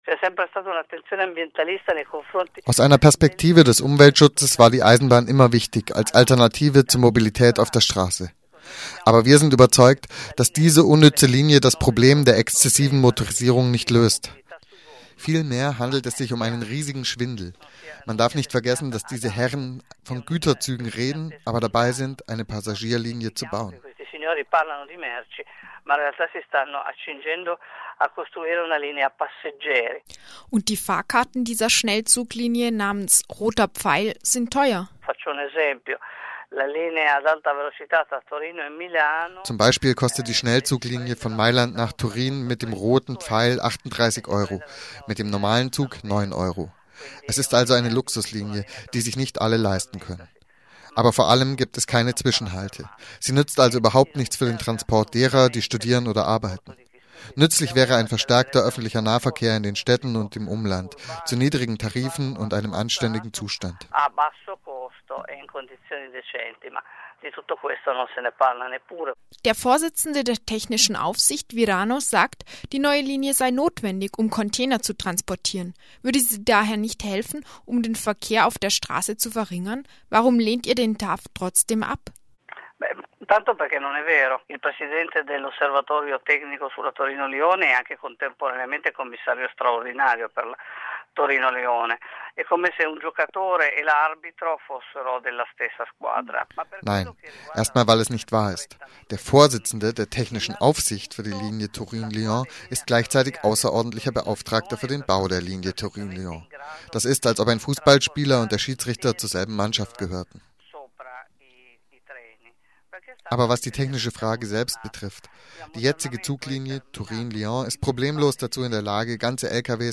Im Interview erwähnter Video-Bericht eines Aktivisten über seine Misshandlung durch die Polizei - leider nur in italienischer Sprache